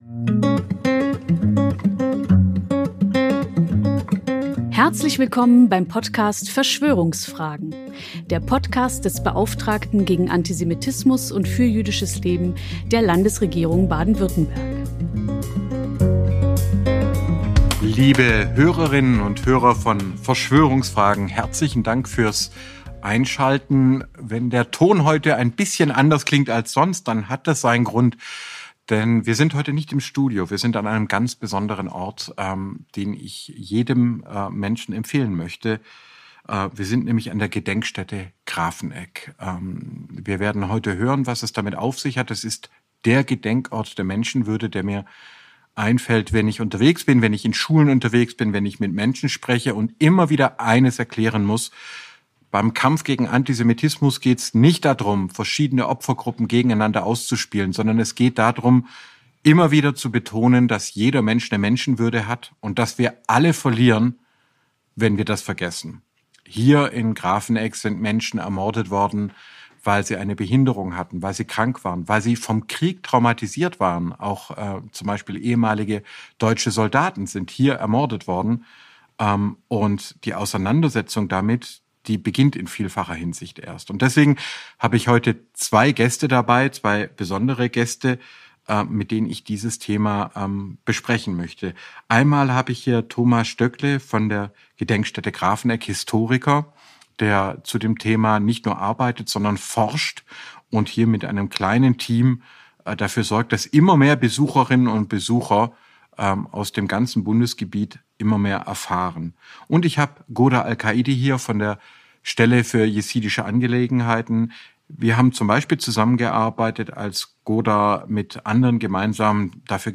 Hinweis: Diese Podcastfolge wurde in der Bibliothek der Gedenkstätte Grafeneck aufgenommen, die Aufnahme kann daher von der Tonqualität anderer Folgen abweichen.